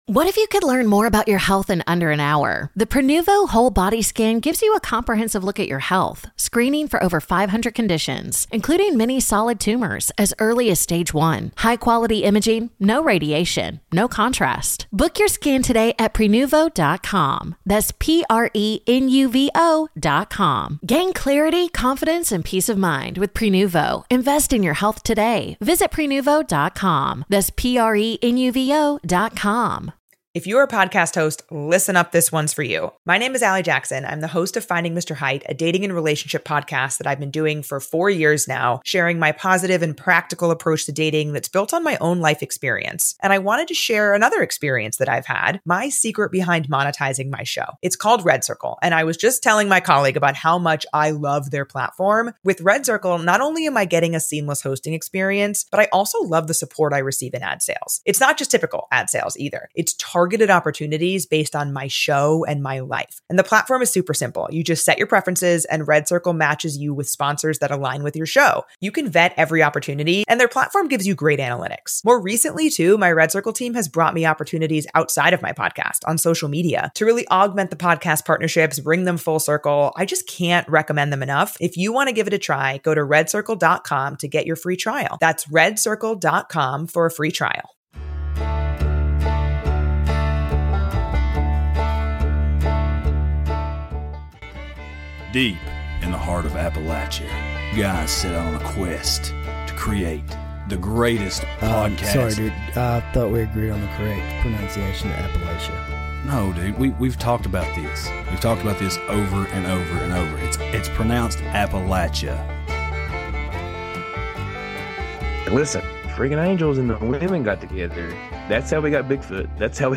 Weird technical difficulties the whole time.